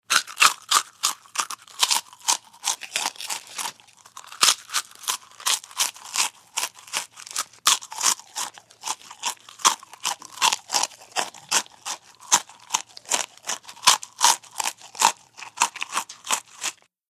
DINING - KITCHENS & EATING CHICKEN: INT: Bite into and crispy chew.